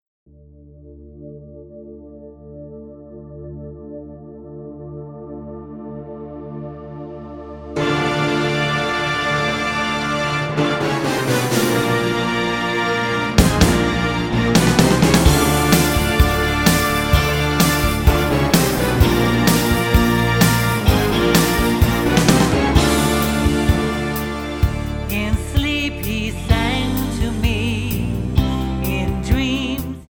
Tonart:Dm-Gm-Em-Fm-Gm-Am Multifile (kein Sofortdownload.
Die besten Playbacks Instrumentals und Karaoke Versionen .